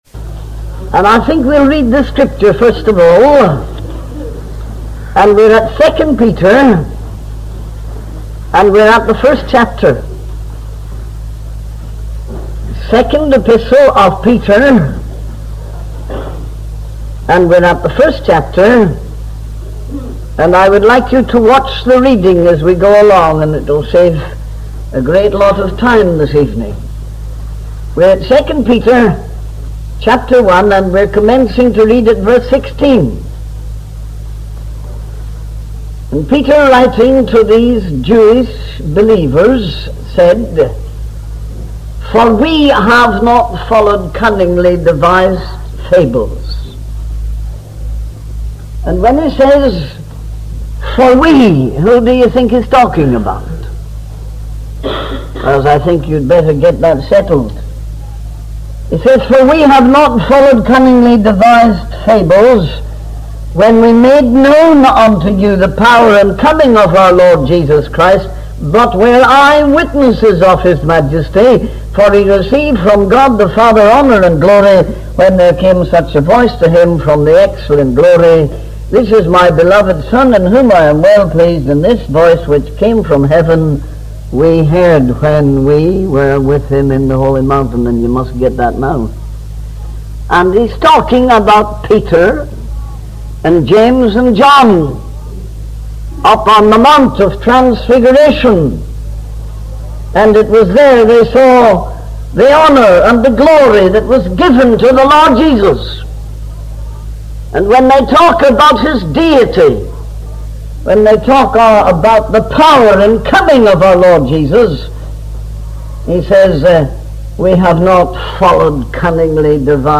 In this sermon, the preacher focuses on the importance of using parables to convey the word of God. He emphasizes the need to study and understand the Bible, despite opposition from Rome, communists, and modernists.